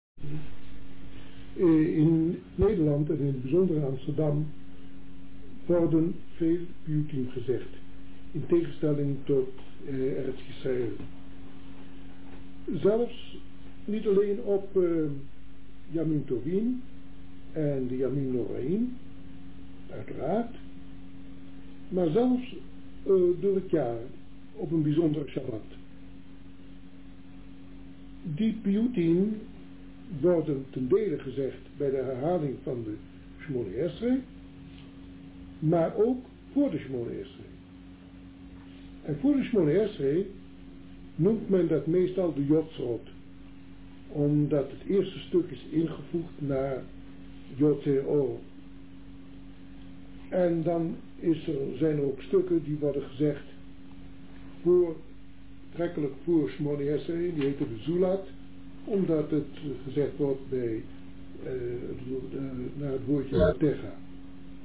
Opnames opperrabbijn Aharon Schuster
פיוטים